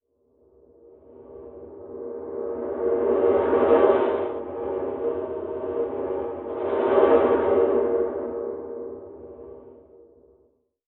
TerrorNoise_3.wav